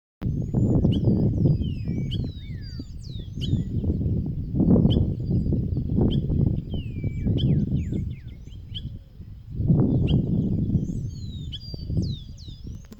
Saracuruçu (Aramides ypecaha)
Nome em Inglês: Giant Wood Rail
Fase da vida: Adulto
Localidade ou área protegida: Colonia Carlos Pellegrini
Condição: Selvagem
Certeza: Observado, Gravado Vocal